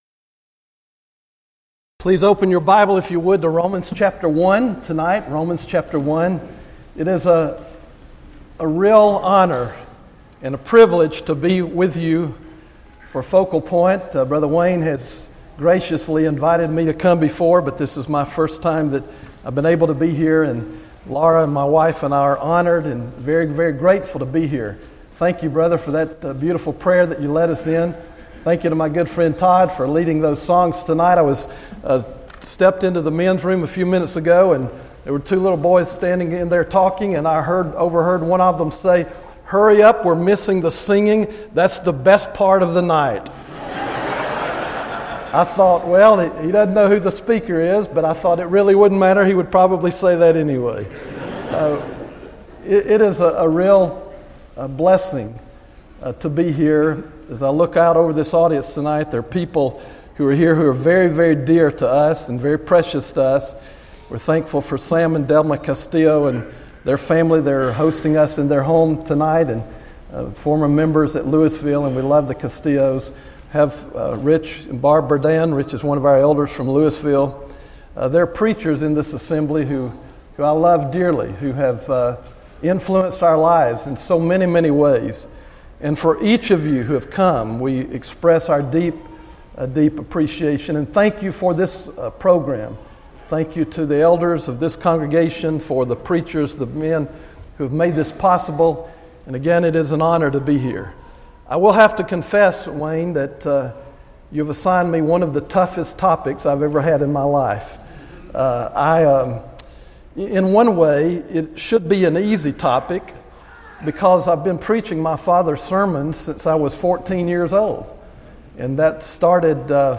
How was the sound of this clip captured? Event: 2016 Focal Point Theme/Title: Preacher's Workshop